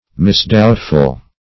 Search Result for " misdoubtful" : The Collaborative International Dictionary of English v.0.48: Misdoubtful \Mis*doubt"ful\, a Misgiving; hesitating.